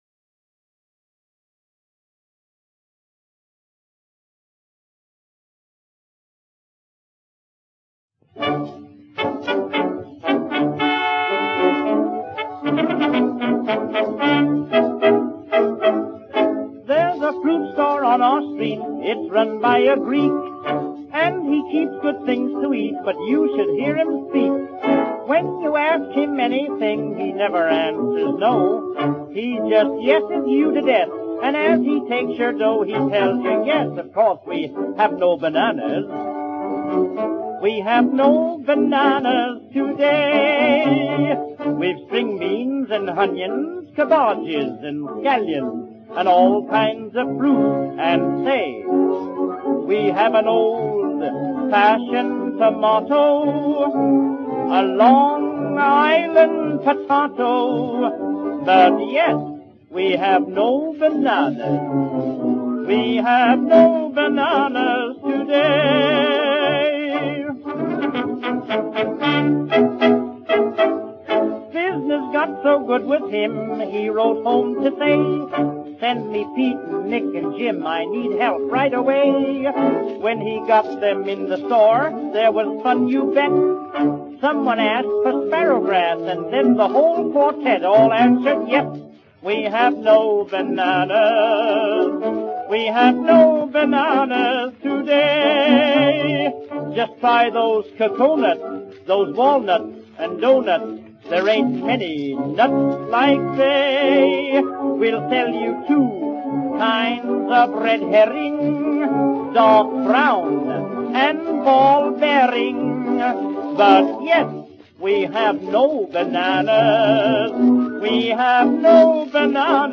There were comic songs (like